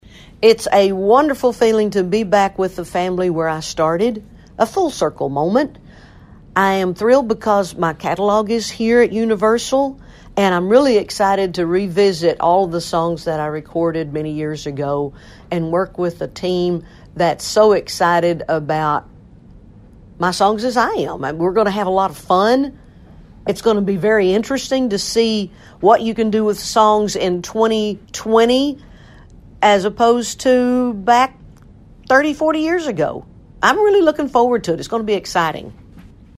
Audio / Reba McEntire talks about being back home at Universal Music Group.